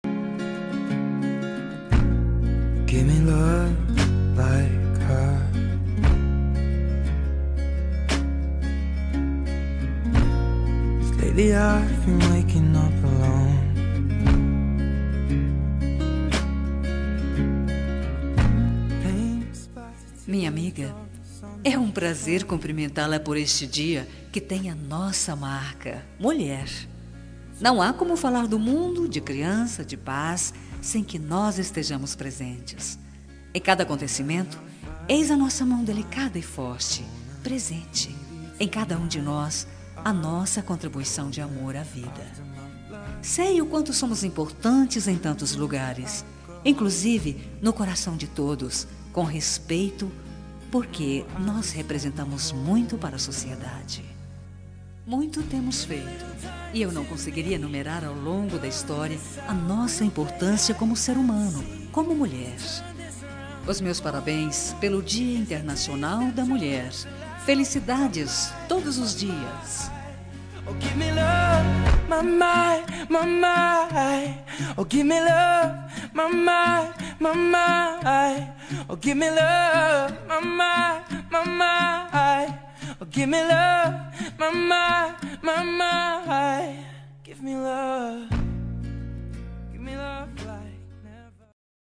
Dia das Mulheres Para Amiga – Voz Feminina – Cód: 5345 – Linda.